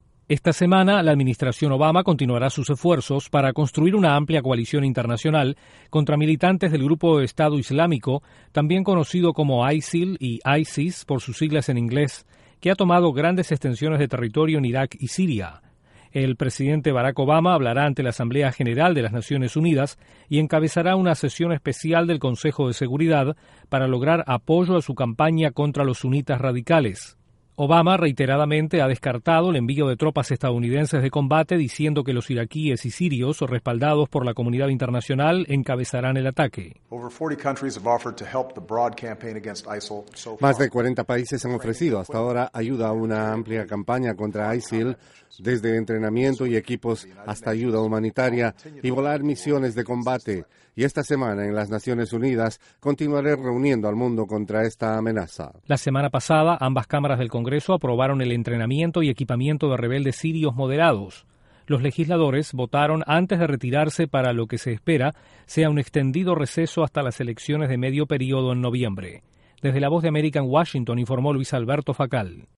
El presidente Barack Obama busca concretar una amplia coalición contra el grupo extremista Estado Islámico. Desde la Voz de América en Washington informa